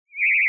It uses an FM Synth that I wrote (Phase Modulation, more accurately) that has a carrier/modulator pair, an LFO (for either pitch or volume uses) and some frequency sweep options and an ASR envelope.
raygun2.wav